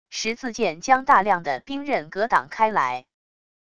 十字剑将大量的冰刃格挡开来wav音频